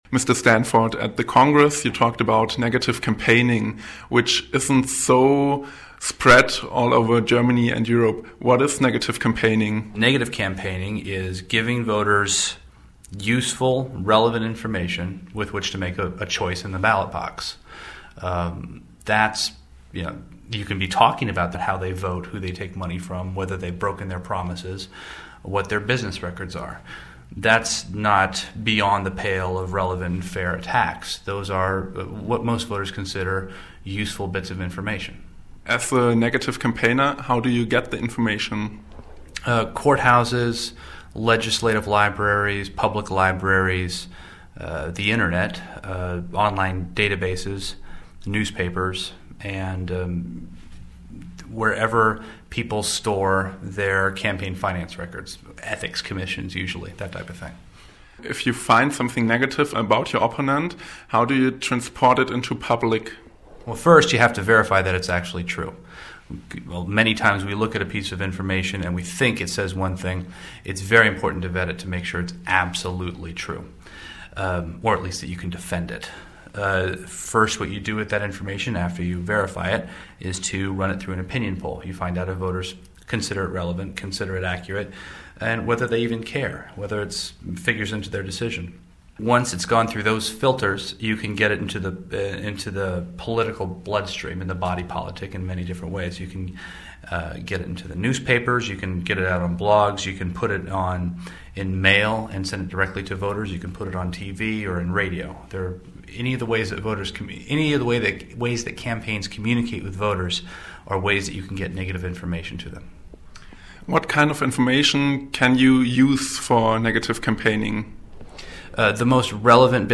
Konferenz für politische Kommunikation